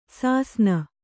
♪ sāsna